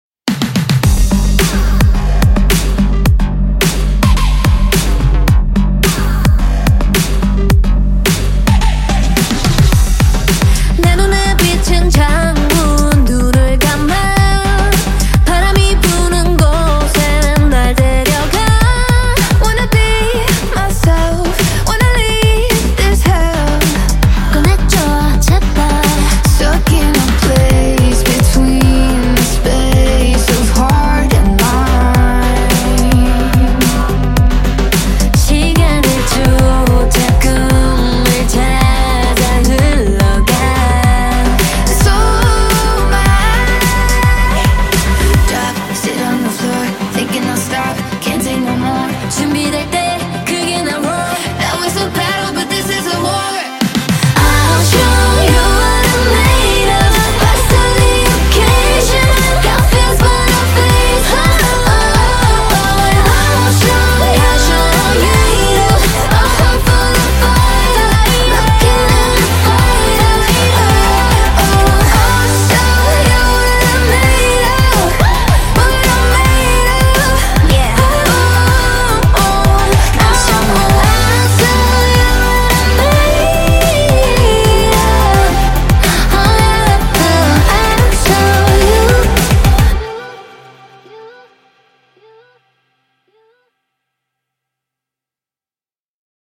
BPM54-108
MP3 QualityMusic Cut